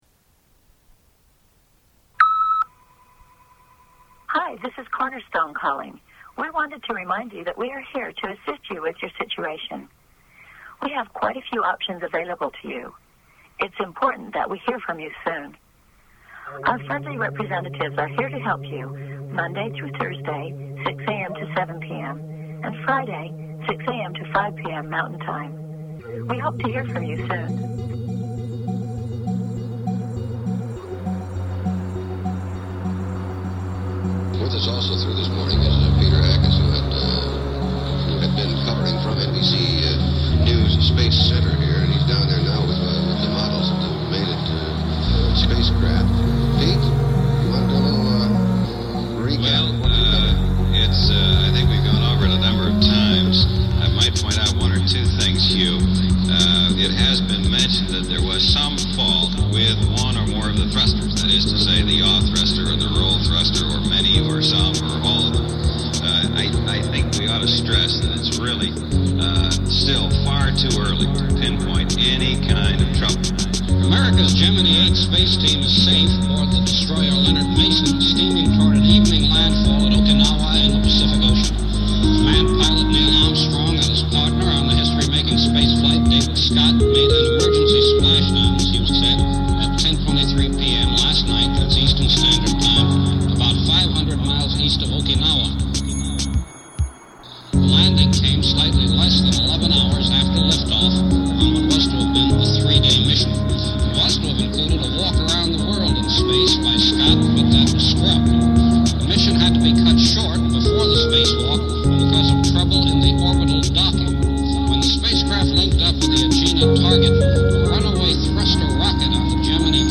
You've made this album delightfully bizarre.